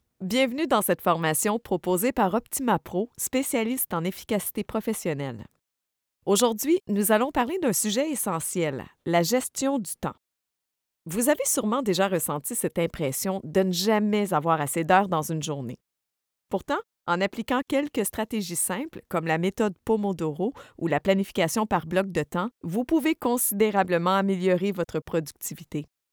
Female
French (Canadian)
Yng Adult (18-29), Adult (30-50)
Narration
0529Narration.mp3